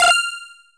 game_next.mp3